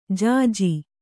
♪ jāji